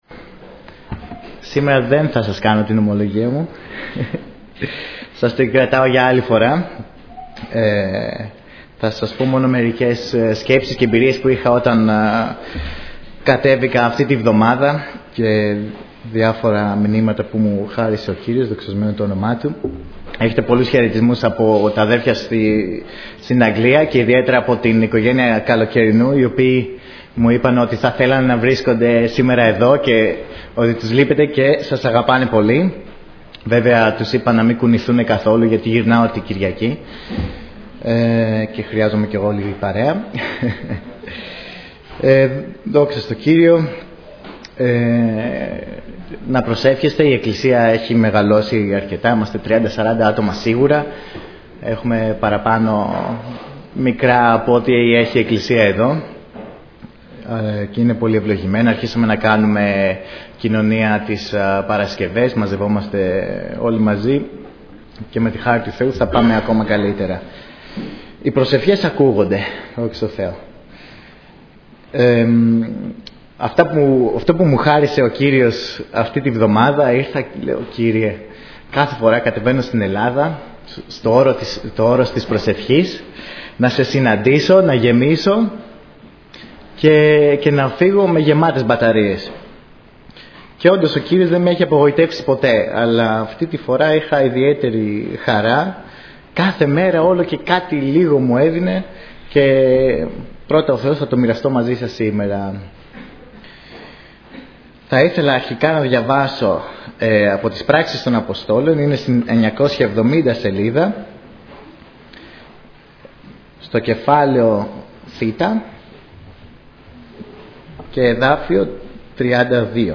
Κηρύγματα Ημερομηνία